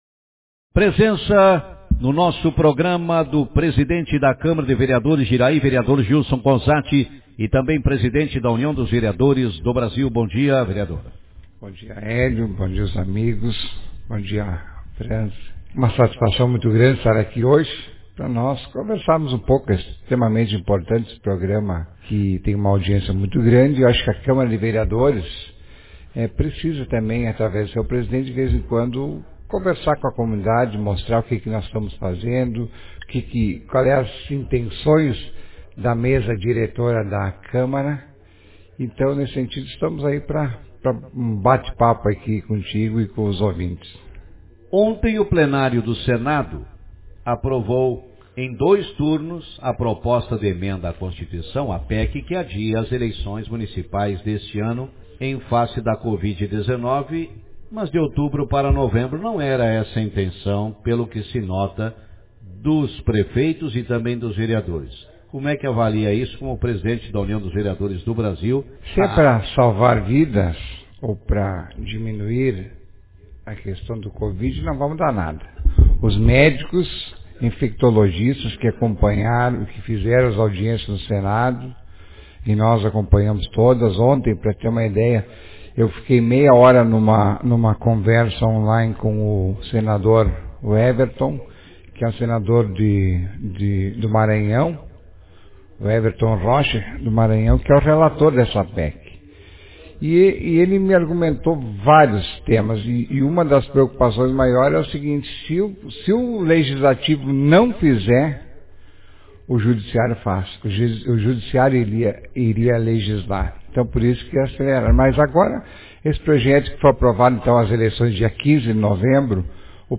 Na manhã desta quarta-feira,24, no programa Café com Notícias, conversamos com o vereador Gilson Conzatti, presidente da Câmara de Vereadores de Iraí e presidente da União dos Vereadores do Brasil – UVB, que na ocasião, falou ser contra a realização de eleições municipais neste ano.